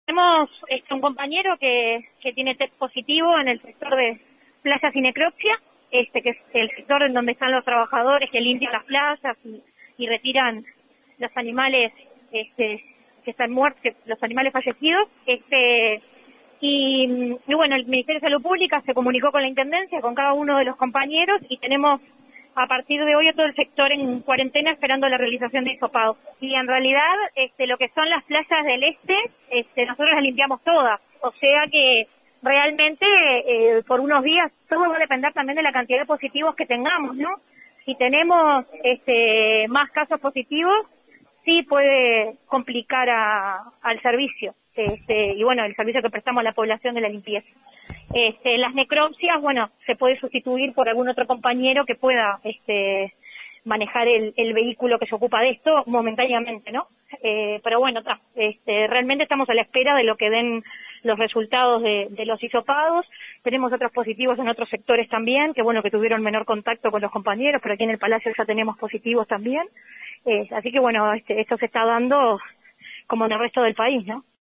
en diálogo con 970 Noticias confirmó que un compañero dio test positivo en el sector de playas y necropsias.